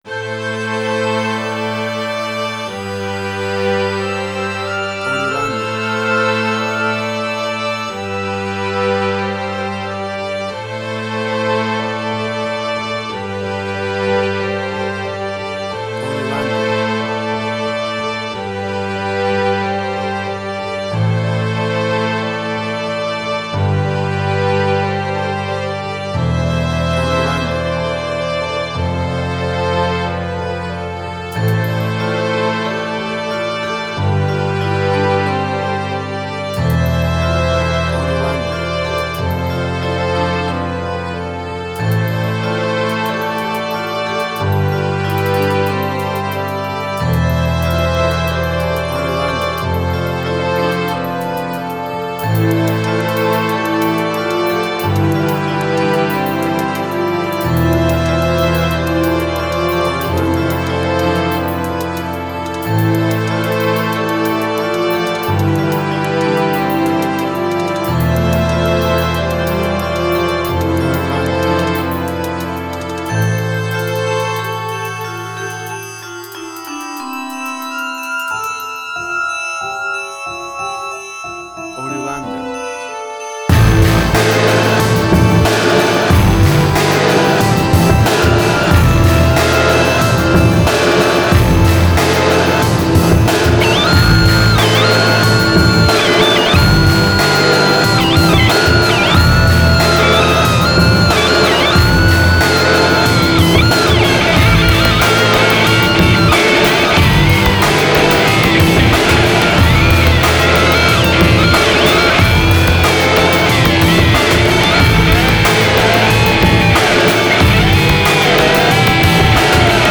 emotional music
Tempo (BPM): 92